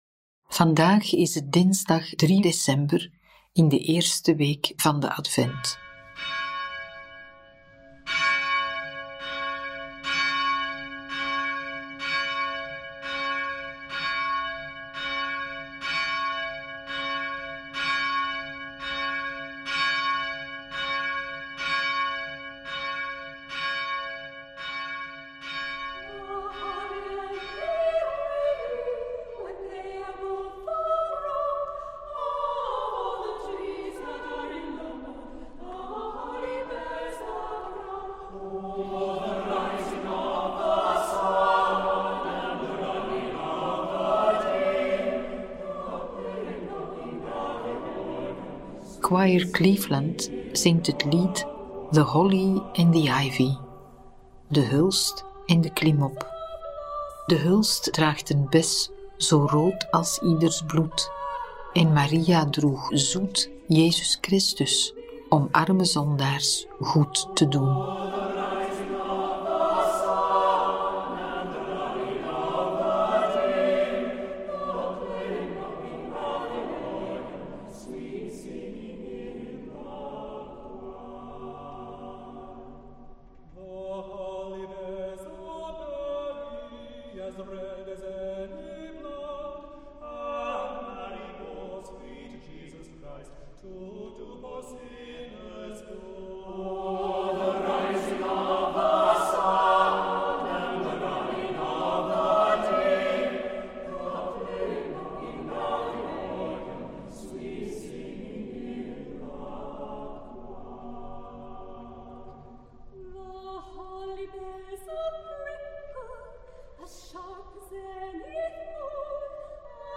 Bidden Onderweg brengt je dichter bij God, met elke dag een nieuwe gebedspodcast. In de meditaties van Bidden Onderweg staan Bijbelteksten central. De muzikale omlijsting, overwegingen y begeleidende vragen helpen je om tot gebed te komen.